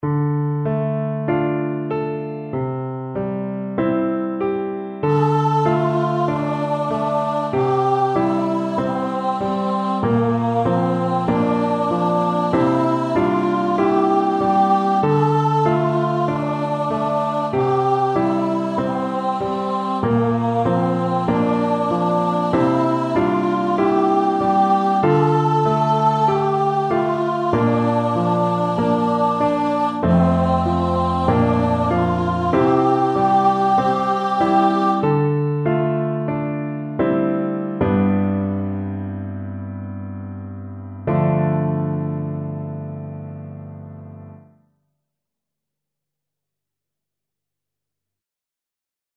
a sung meditation